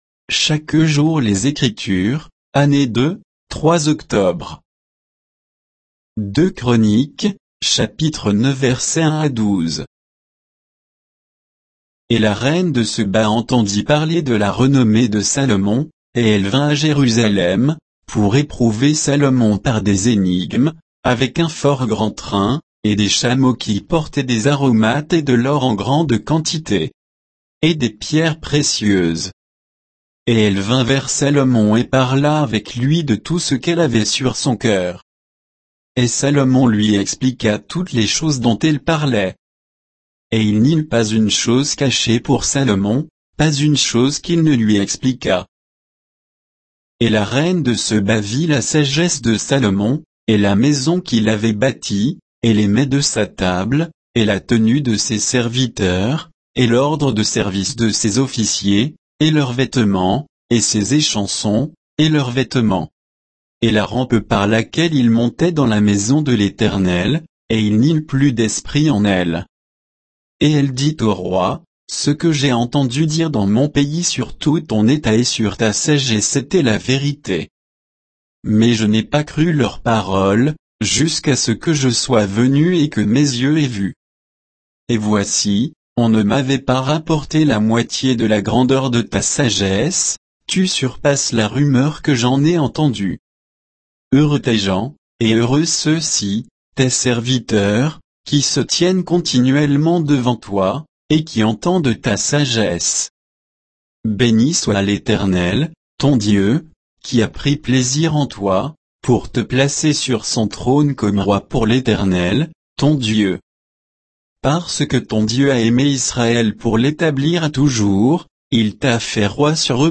Méditation quoditienne de Chaque jour les Écritures sur 2 Chroniques 9, 1 à 12